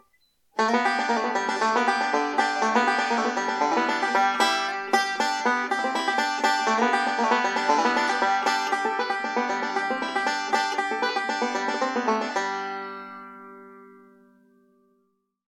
Oscar Schmidt OB5SP-A Banjo - $350 + $75 S/H (US only)
And the tone you get from this banjo is really amazing!